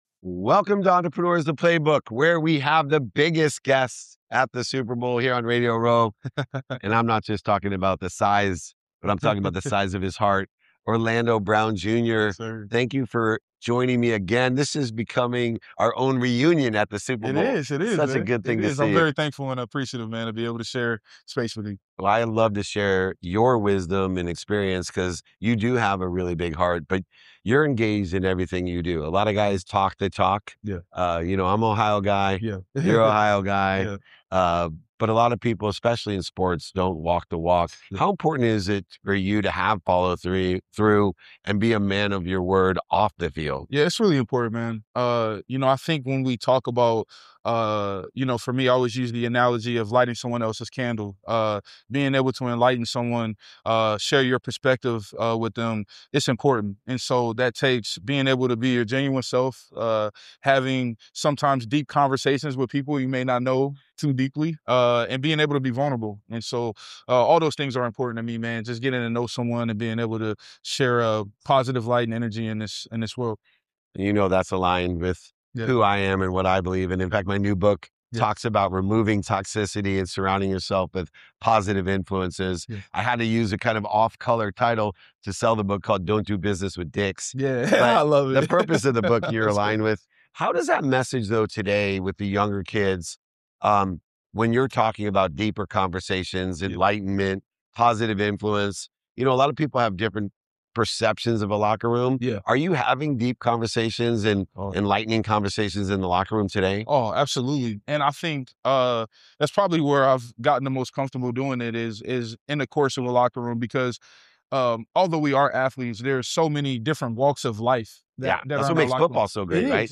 In today’s episode, I sit down with Orlando Brown Jr, an NFL offensive tackle, to talk about what it really means to follow through and lead with intention on and off the field.